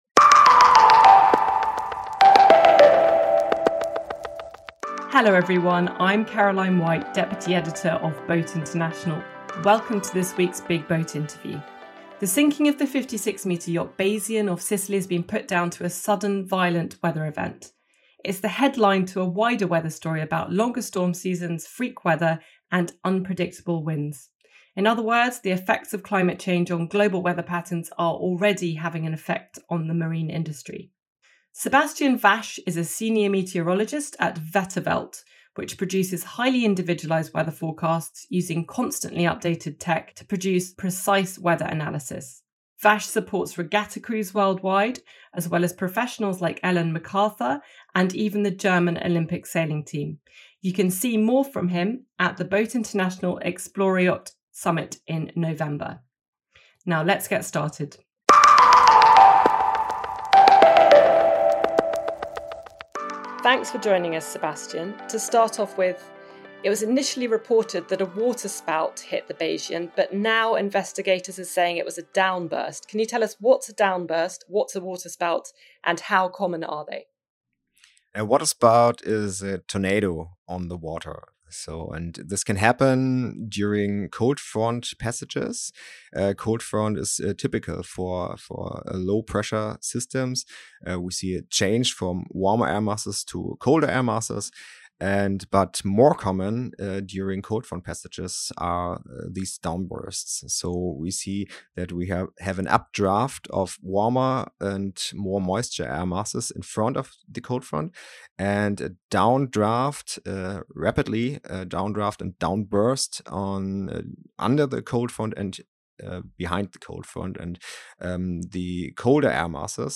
Big BOAT Interview: A meteorologist unpacks the weather conditions surrounding the sinking of Bayesian